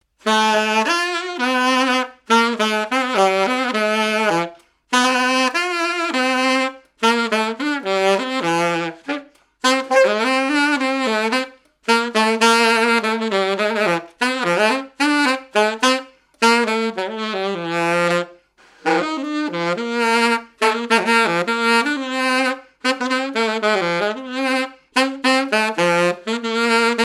Mémoires et Patrimoines vivants - RaddO est une base de données d'archives iconographiques et sonores.
danse : quadrille : chaîne anglaise
activités et répertoire d'un musicien de noces et de bals
Pièce musicale inédite